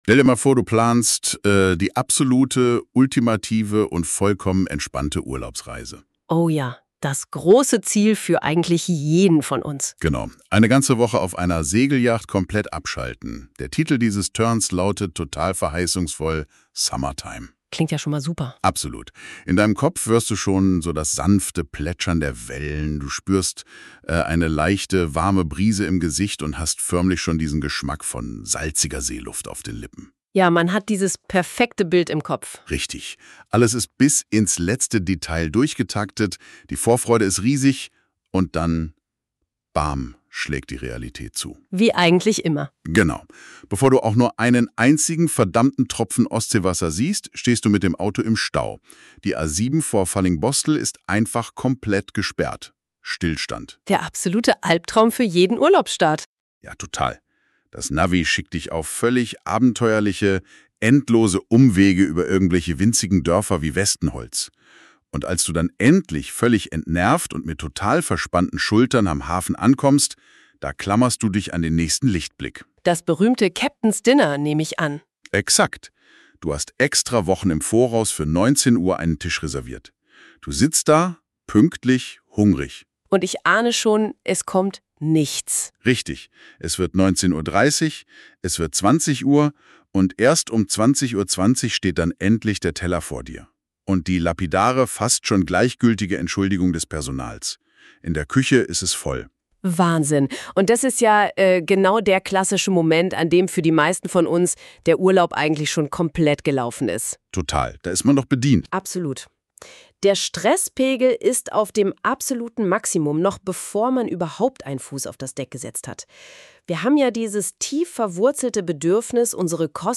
Hier das Logbuch und ein von NotebookLM daraus entwickelter Podcast